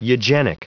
Prononciation du mot eugenic en anglais (fichier audio)
Prononciation du mot : eugenic